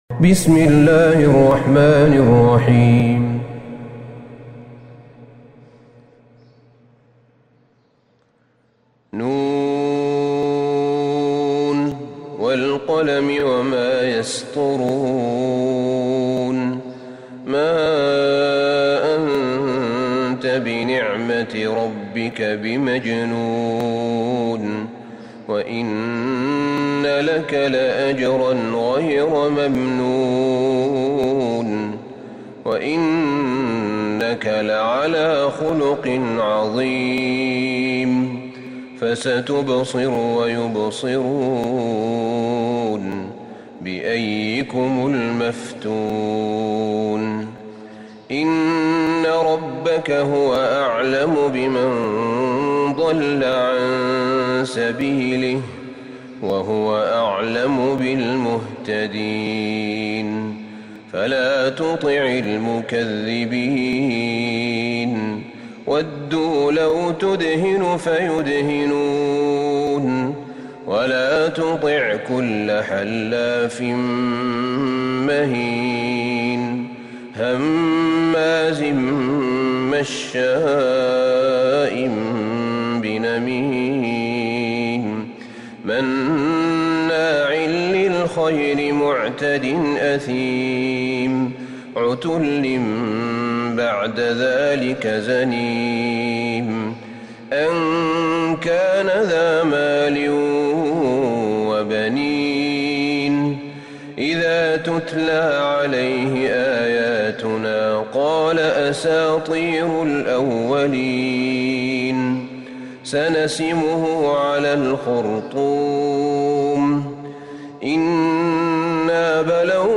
سورة القلم Surat Al-Qalam > مصحف الشيخ أحمد بن طالب بن حميد من الحرم النبوي > المصحف - تلاوات الحرمين